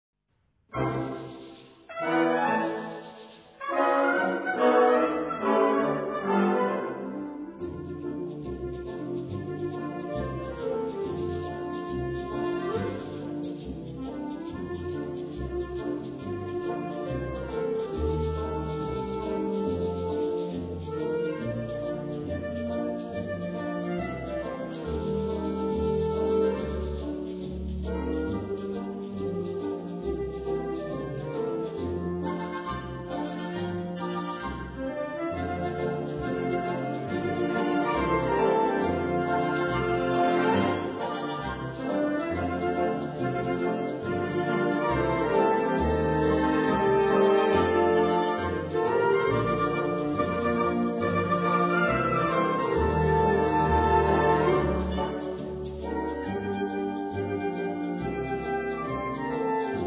Gattung: Solo für Sandpaper-Block und Blasorchester
Besetzung: Blasorchester